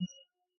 chatMessage.ogg